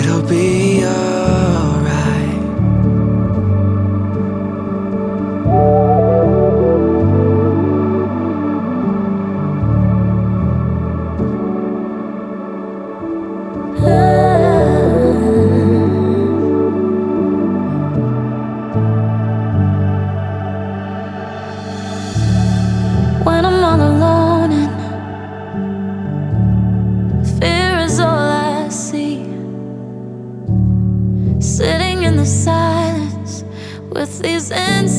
# Gospel